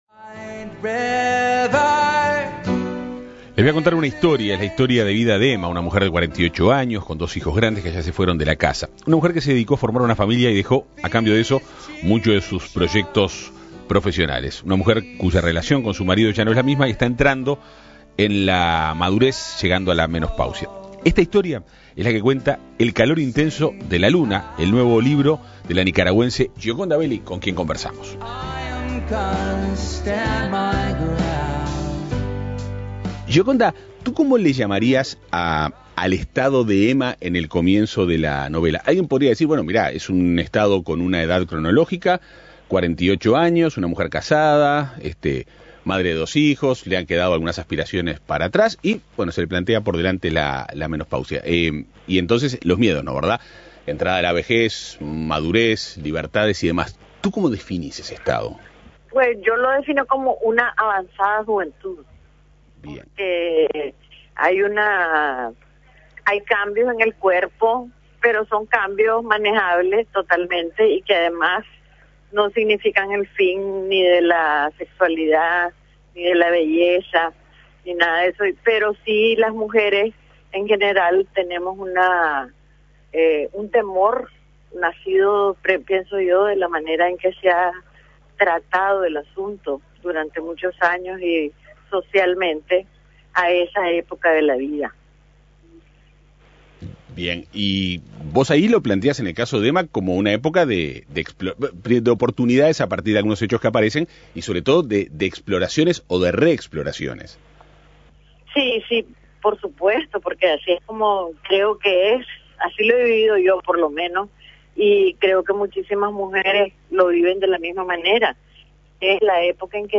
Entrevista a Gioconda Belli
Hoy en El Espectador conversamos con la autora del libro Gioconda Belli.